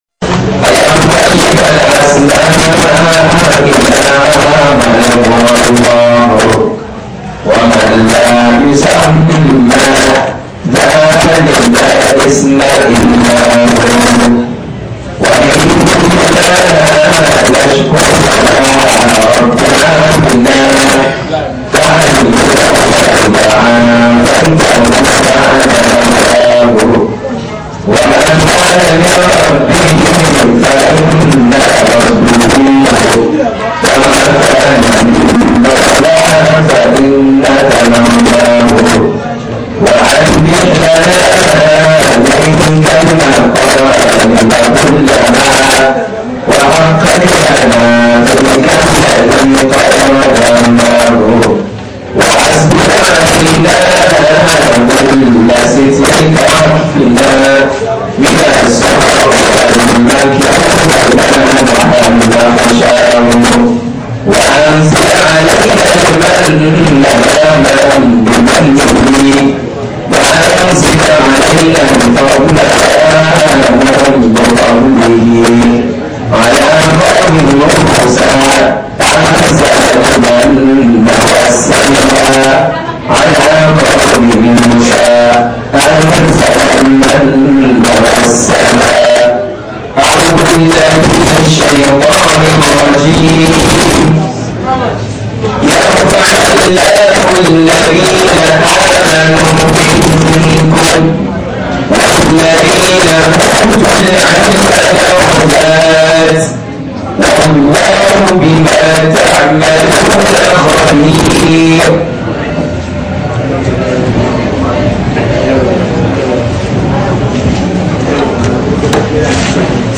2 lectures